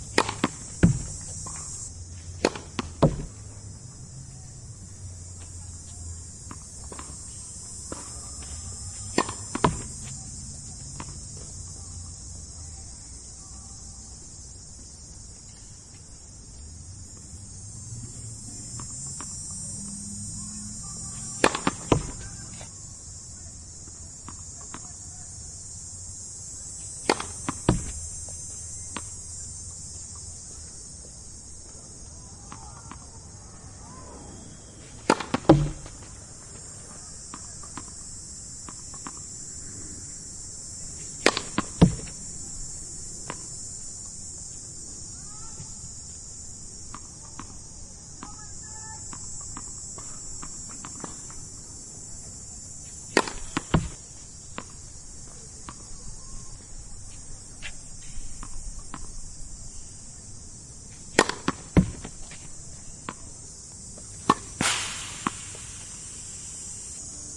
大球弹跳
描述：这是一个被弹跳的大球的记录，例如排球。 记录仪：TASCAM DR40
标签： 运动 球拍 弹跳 弹跳 地板 OWI
声道立体声